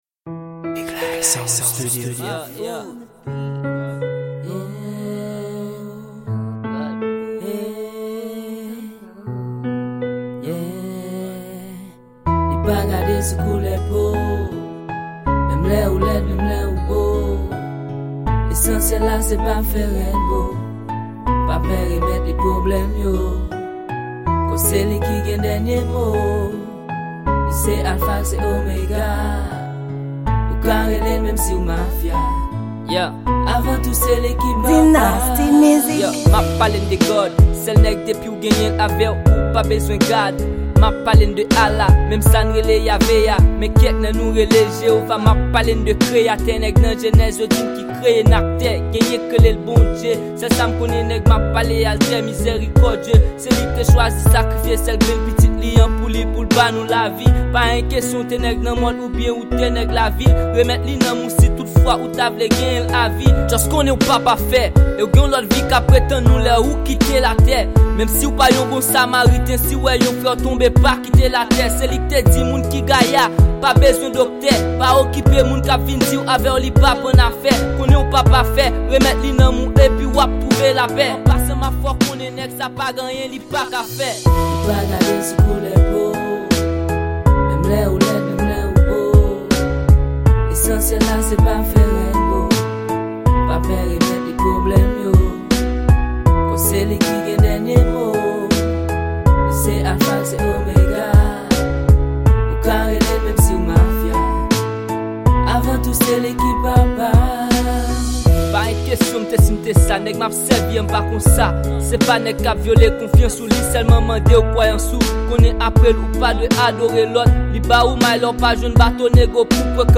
Genre: Rap Gospel